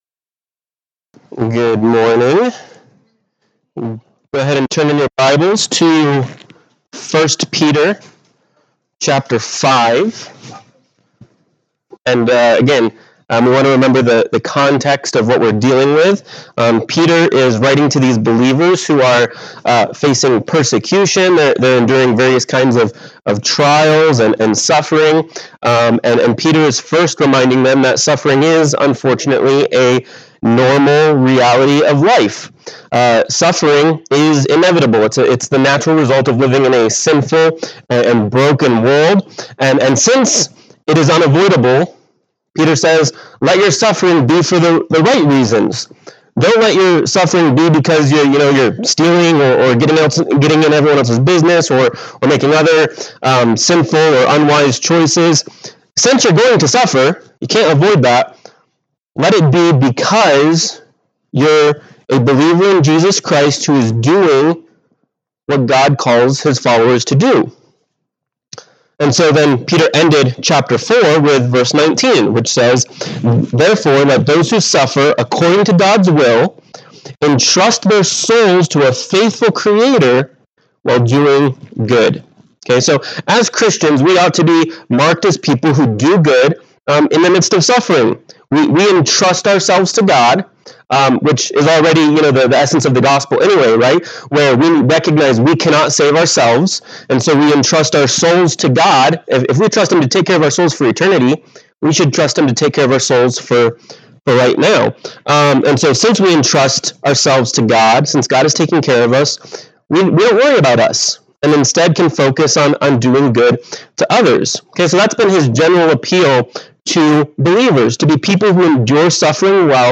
1 Peter 5:1-5 Service Type: Sunday Morning Worship « 1 Peter 4:12-19 1 Peter 5:6-14